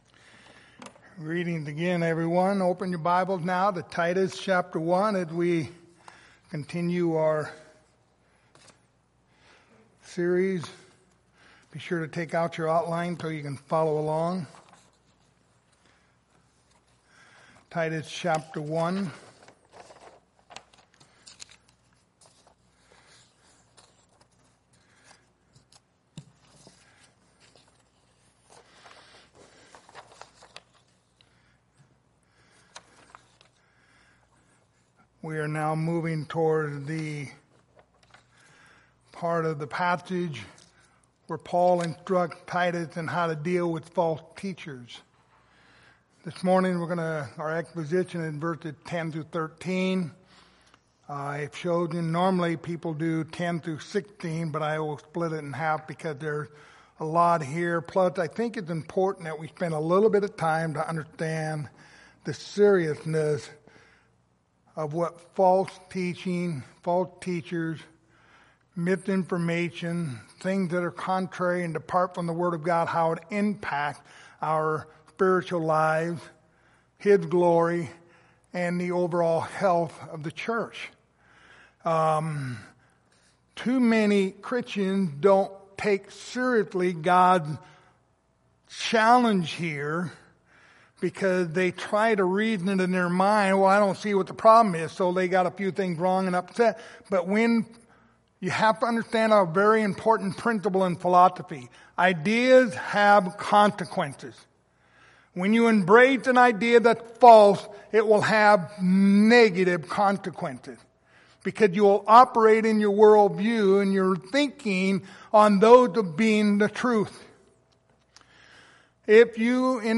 Passage: Titus 1:10-13 Service Type: Sunday Morning